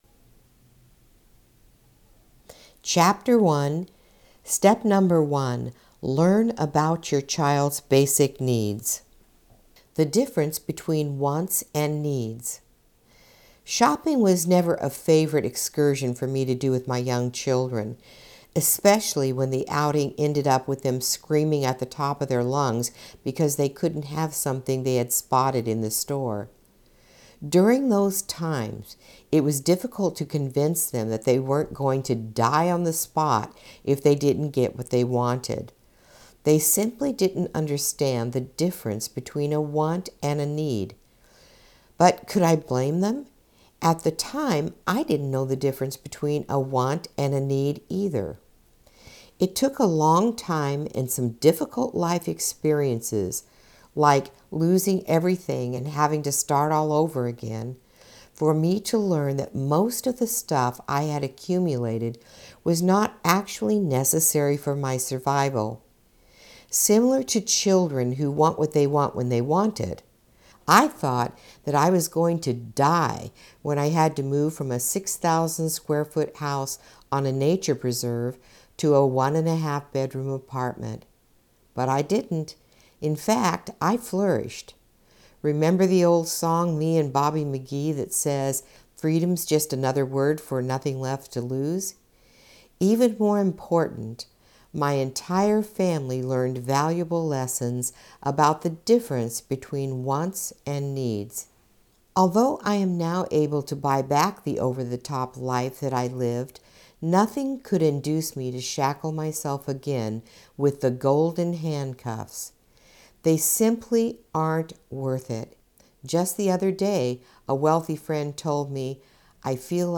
AUDIO BOOK ABOUT MEETING A CHILDS NEED FOR PARENTS